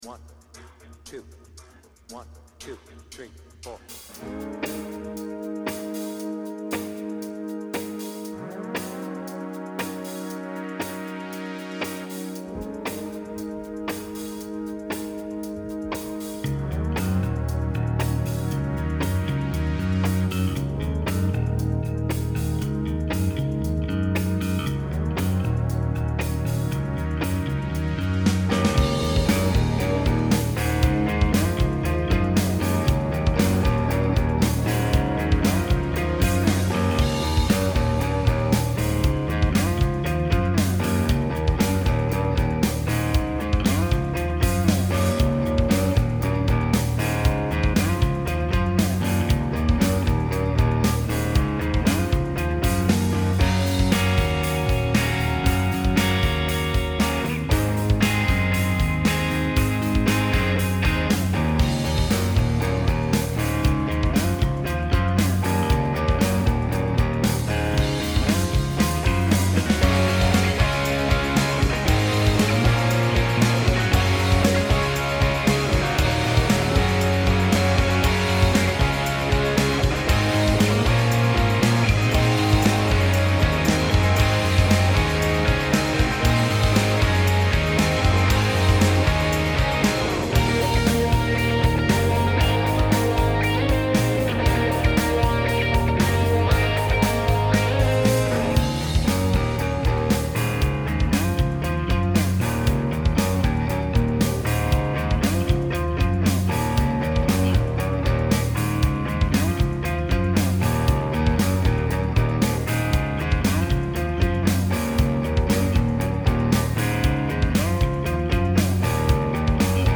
BPM : 116
Tuning : Eb